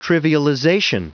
Prononciation du mot : trivialization